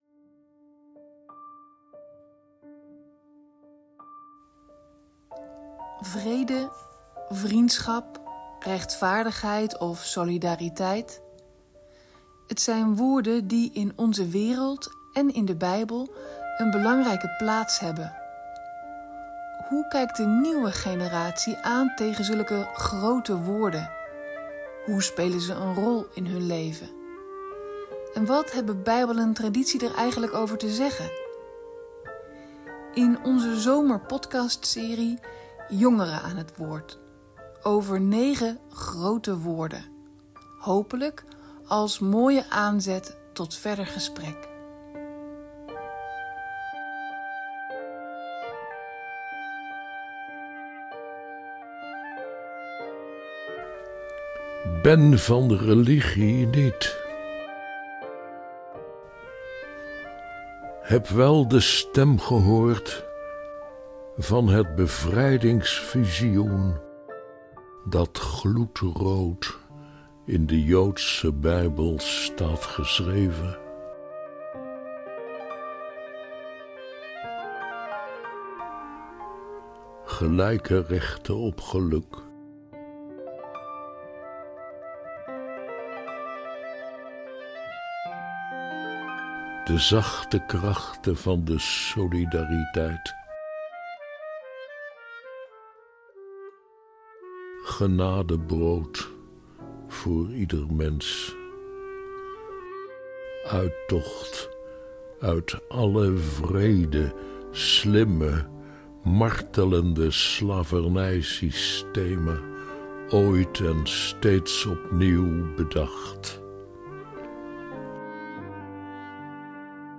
Deze week staat het woord SOLIDARITEIT centraal.Wat betekent dat eigenlijk? De drie jongeren aan tafel wisten het niet goed.